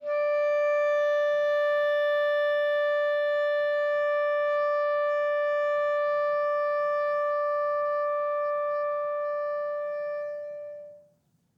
Clarinet
DCClar_susLong_D4_v2_rr1_sum.wav